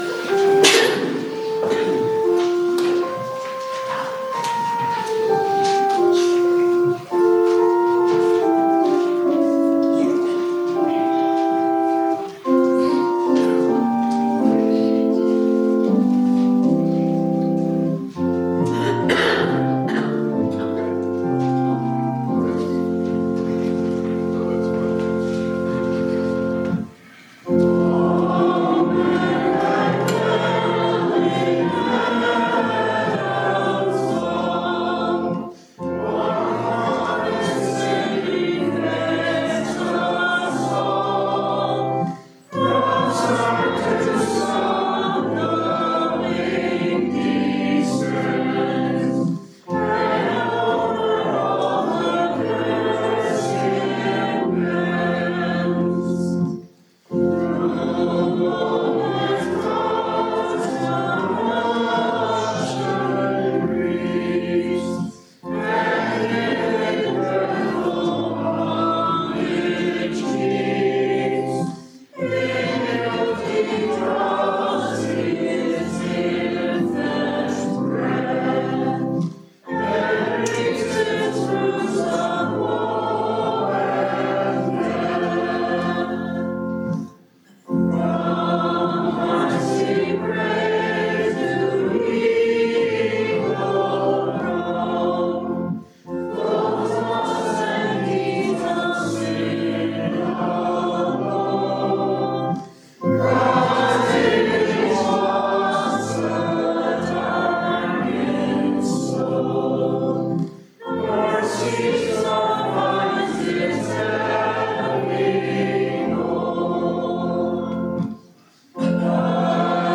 Zion Worship Mar 9 2025